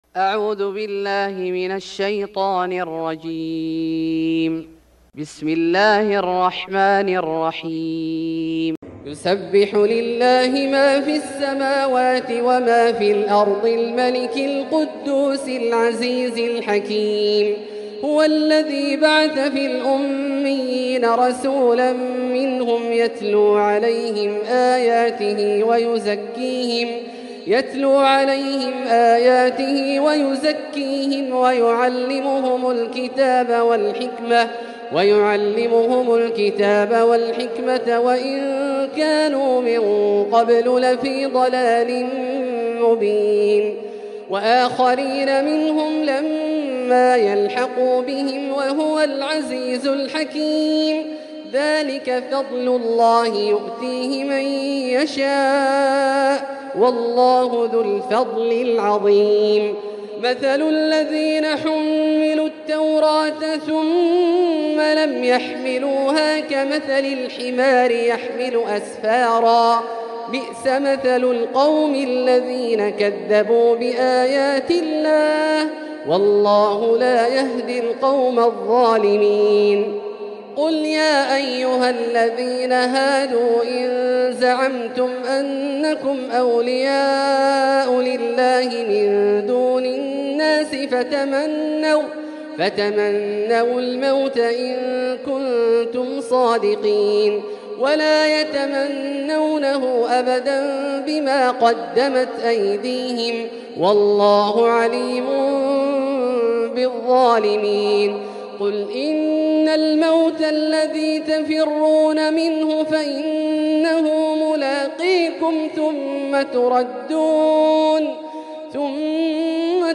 سورة الجمعة Surat Al-Jumu’ah > مصحف الشيخ عبدالله الجهني من الحرم المكي > المصحف - تلاوات الحرمين